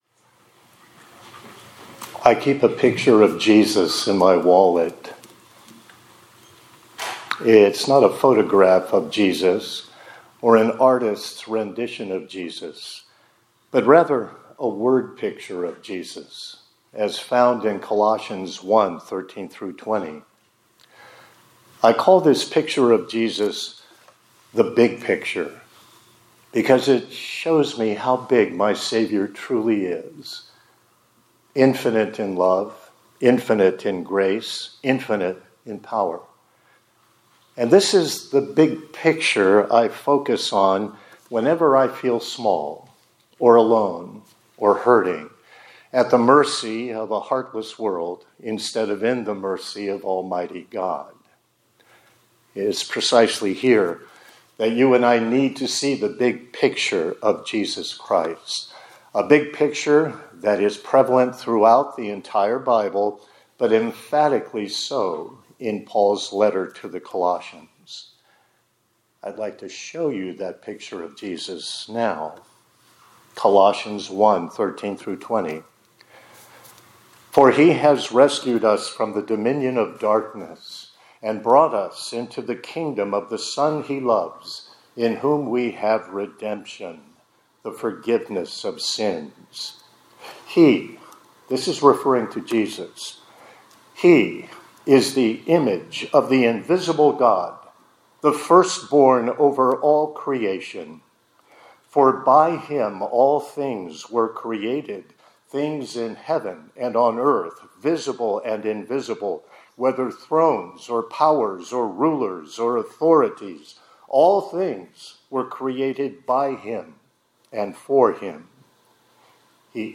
2025-02-17 ILC Chapel — The Big Picture